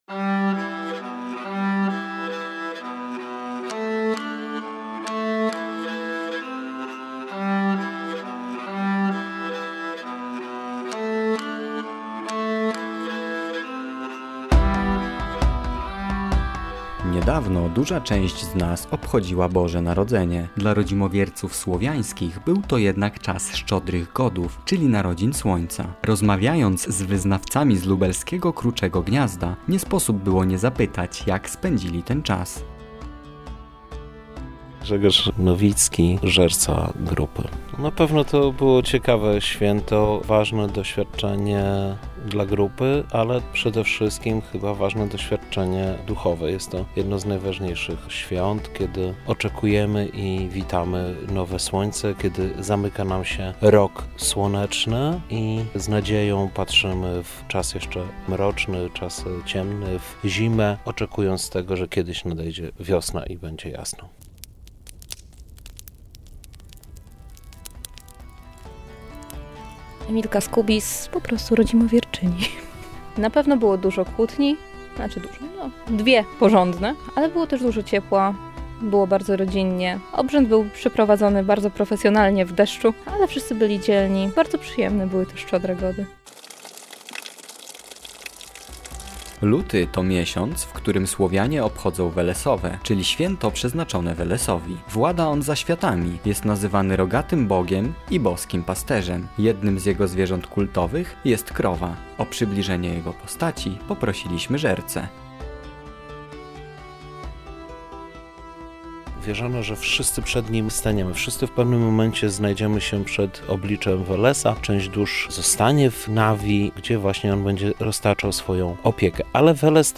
Nasz reporter
od którego członków usłyszycie o ich wierzeniach i tradycjach.